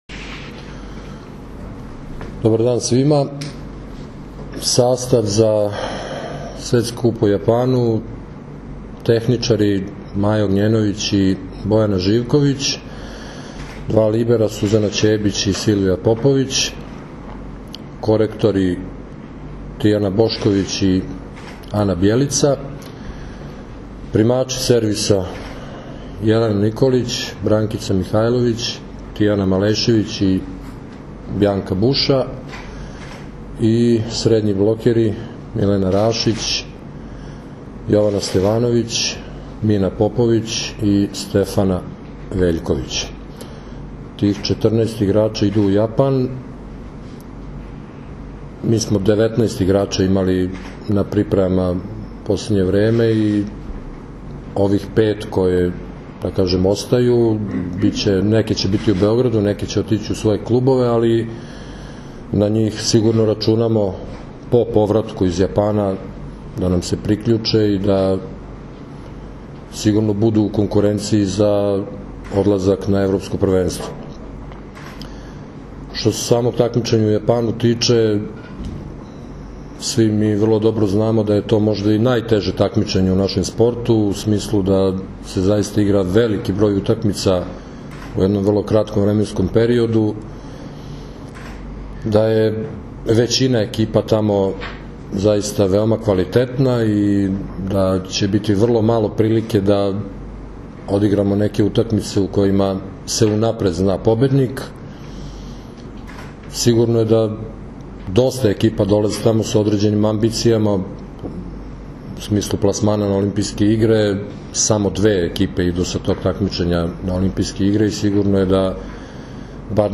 Tim povodom danas je u beogradskom hotelu „M“ održana konferencija za novinare, kojoj su prisustvovali Zoran Terzić, Maja Ognjenović, Jelena Nikolić i Milena Rašić.
IZJAVA ZORANA TERZIĆA